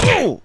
sfx_oof.wav